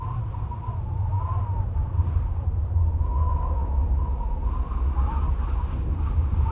moaningwind1.wav